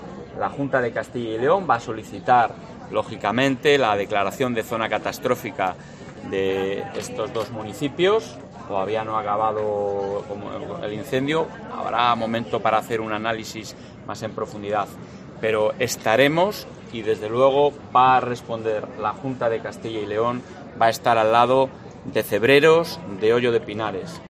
Presidente de la Junta de CyL. Declaración zona catastrófica